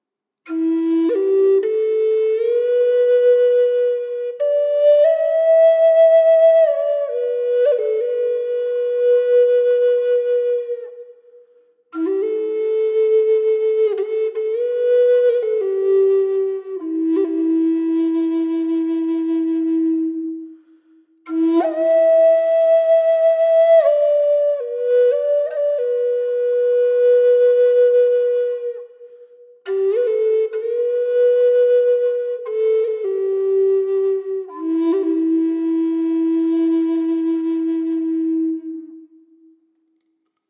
E4 sävellajin Natiivihuilu
Natiiviamerikkalaishuilu E4 sävellajissa. Matala ja lempeä sointitaajuus. Viritetty pentatoniseen molli sävelasteikkoon.
• Vire: Pentatoninen molli (440 hz)
Ääninäyte kaiku/reverb efektillä:
E4_440hz_pentatonicminor_FX.mp3